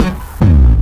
BOOMBASS  -R.wav